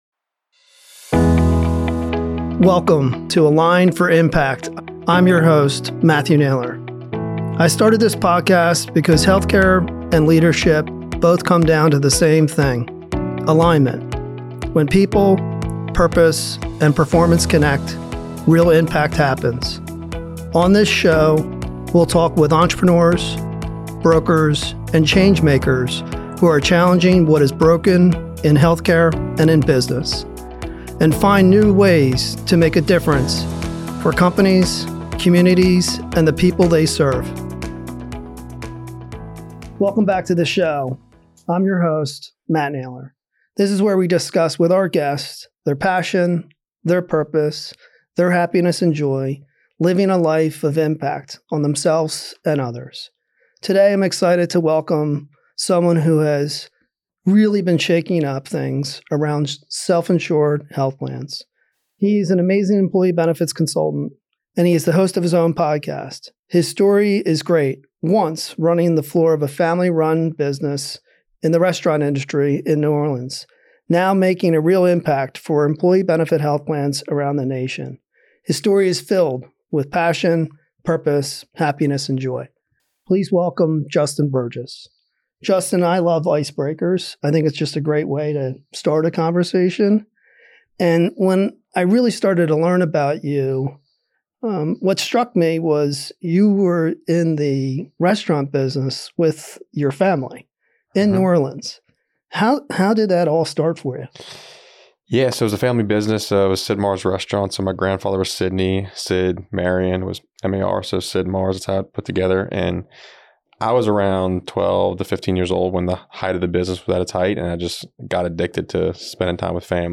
The conversation dives into fixing misalignment in healthcare through transparency, fiduciary responsibility, pharmacy cost containment, and human centered advocacy.